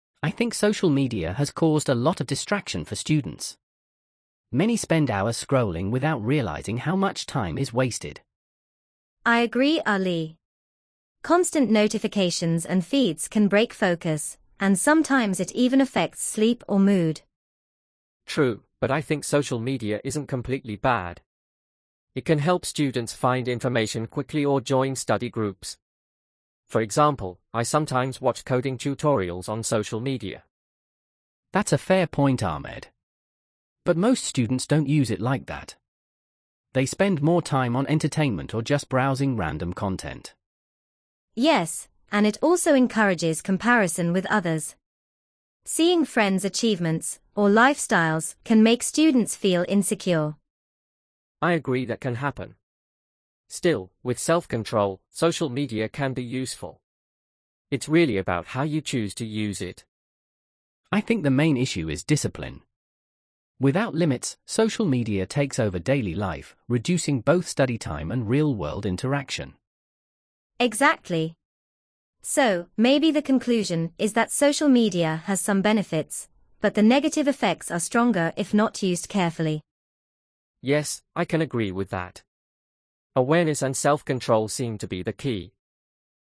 Group Discussions
🤝 Students discuss the impact of social media on their studies and daily life.